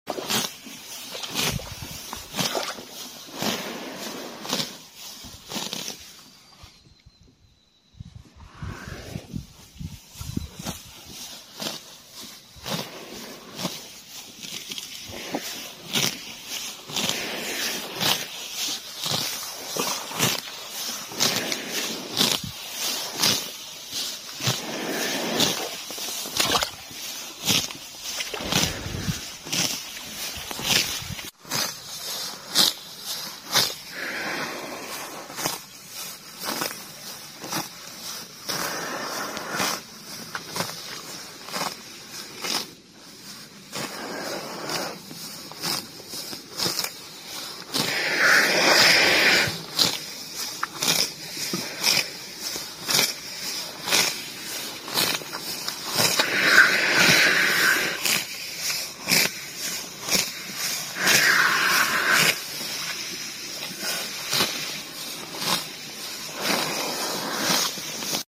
Cow Eating Grass ASMR | sound effects free download
Cow Eating Grass ASMR | Natural Valley